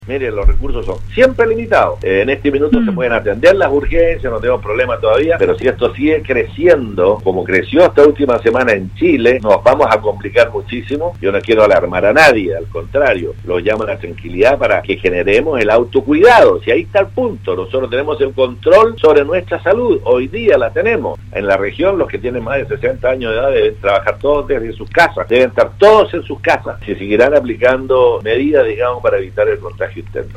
En conversación con Radio Sago, el jefe regional señaló “la capacidad y hospitales son limitados”, añadiendo que puede llegar un punto en el cual no puedan atenderse todas las urgencias.